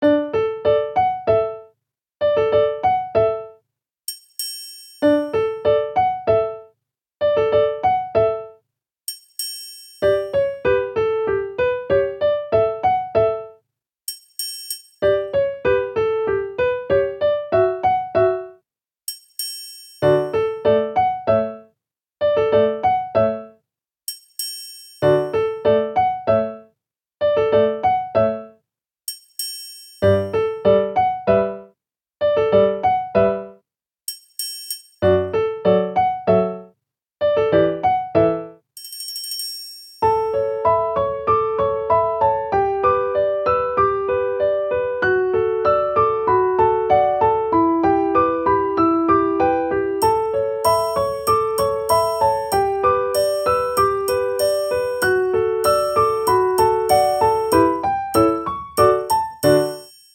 ogg(L) まったり 癒し ピアノ
くつろぐ猫様のためのピアノ曲。